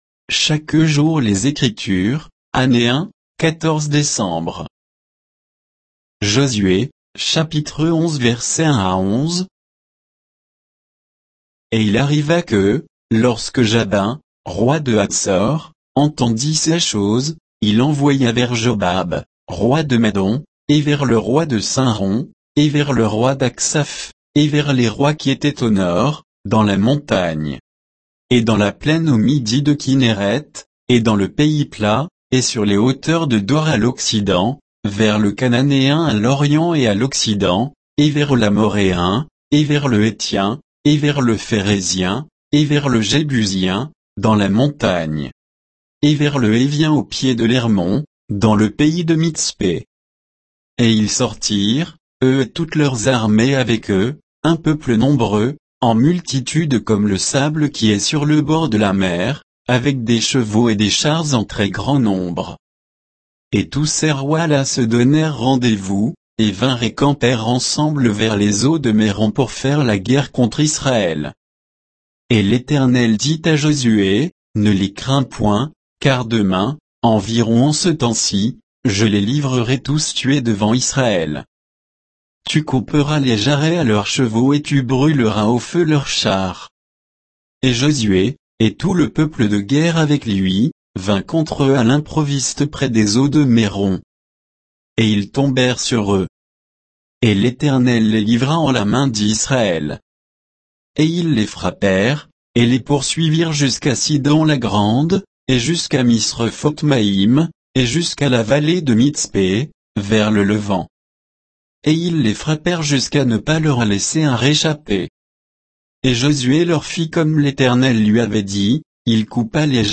Méditation quoditienne de Chaque jour les Écritures sur Josué 11, 1 à 11